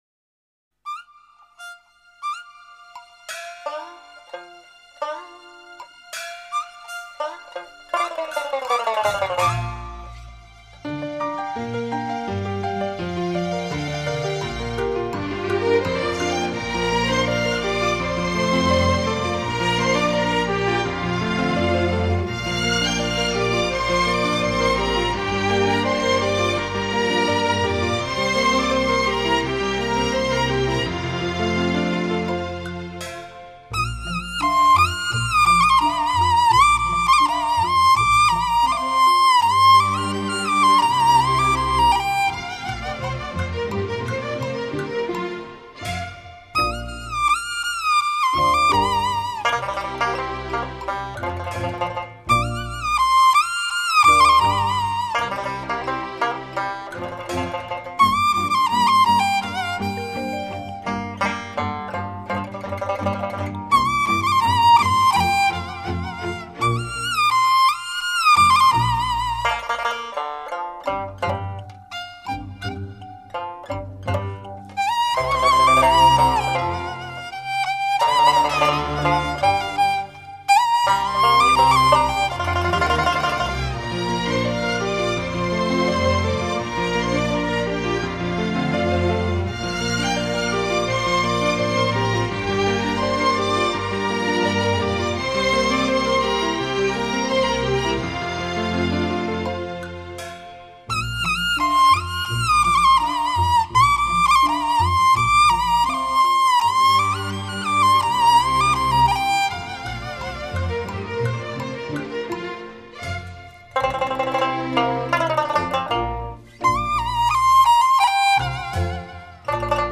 音乐外刚内柔，在奔放、明快之中透露出一种清丽、洒脱之气。
乐队以中国民族乐器为主奏和领奏，辅以西洋管弦乐器及电声乐器。